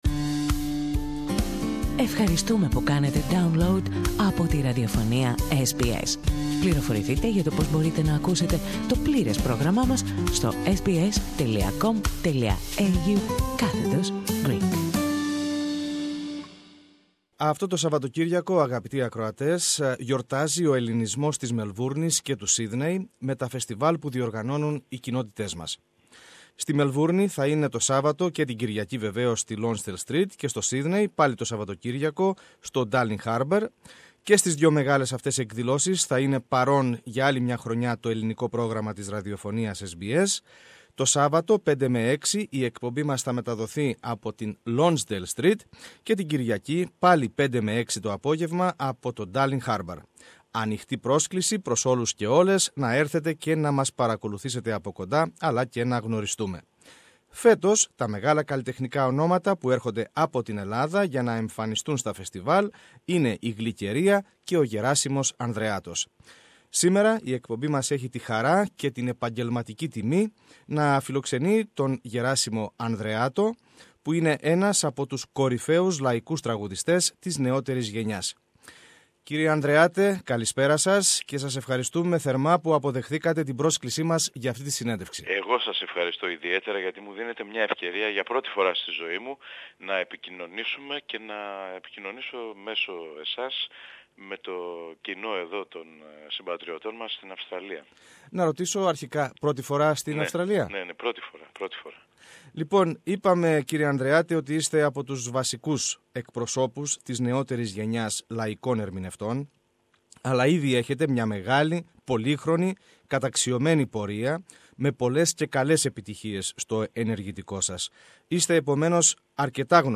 Ο Γεράσιμος Ανδρεάτος μιλά αποκλειστικά στο Ελληνικό Πρόγραμμα